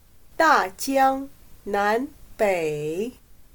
大江南北/Dàjiāngnánběi/Significa que el norte y el sur del gran río se refieren a la vasta área a ambos lados de los tramos medio e inferior del río Yangtze, que generalmente se refiere a toda China.